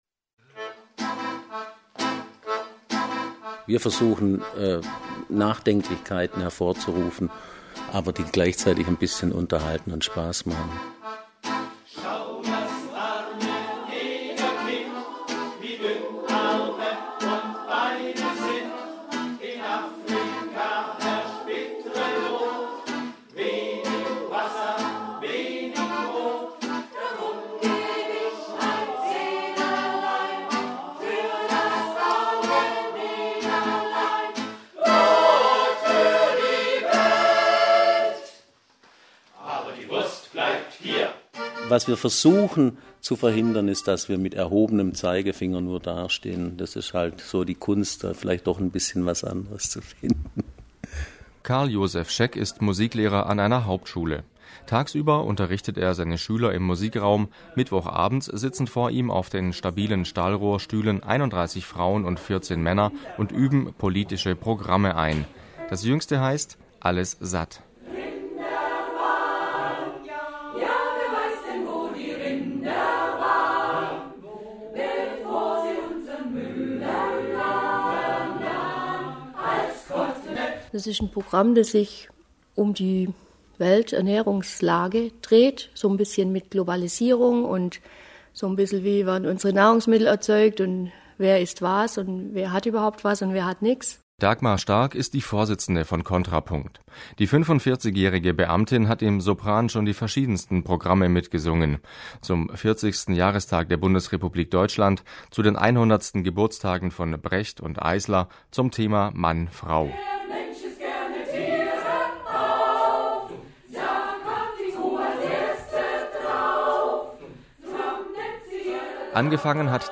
Kontrapunkt e.V. – Chor im Deutschlandradio Kultur
1978 hat sich in Ulm der Gewerkschaftschor 1. Mai gegründet und sich in den letzten 30 Jahren zu dem Ensemble Kontrapunkt entwickelt. Der Chor versteht sich als Gegenstimme - gesellschaftskritisch, unterhaltsam und bewegend.